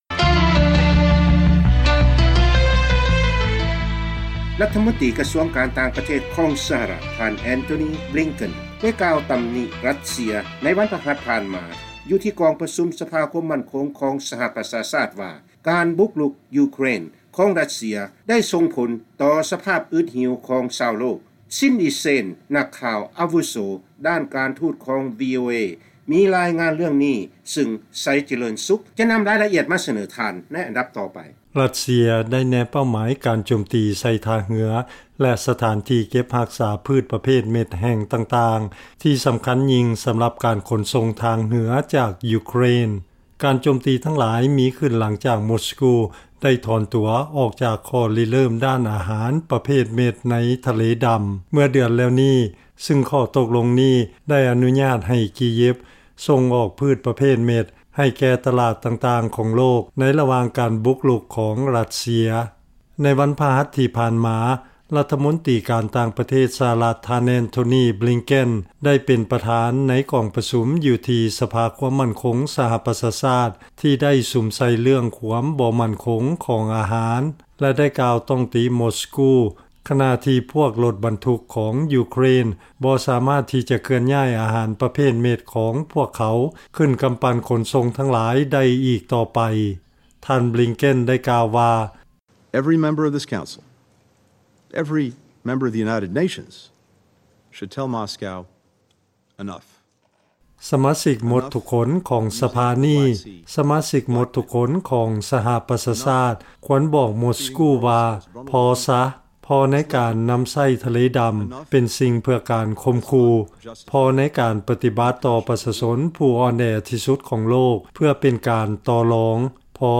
ຟັງລາຍງານ ທ່ານບລິງເກັນ ຊີ້ໃສ່ຣັດເຊຍ ແຕ່ພຽງປະເທດດຽວ ສຳລັບການຮຸກຮານຕໍ່ການສະໜອງອາຫານແກ່ໂລກ